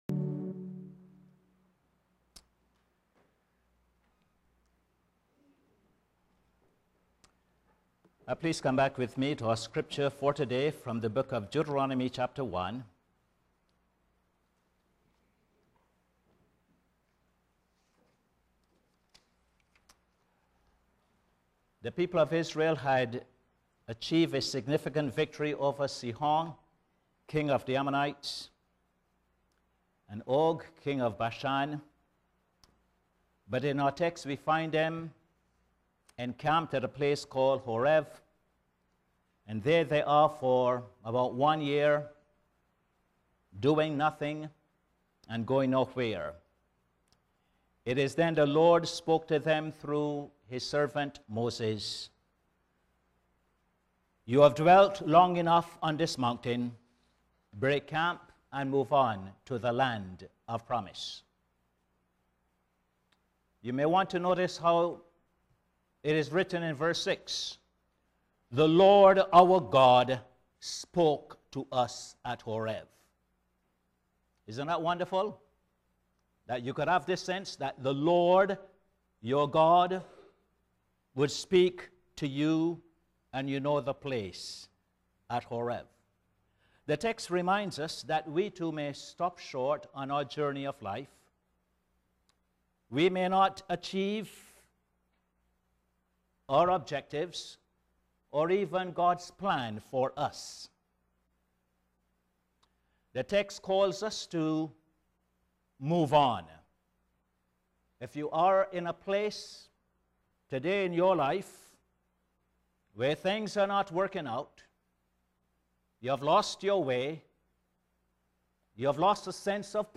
Posted in Sermons on 03. Aug, 2011